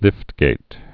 (lĭftgāt)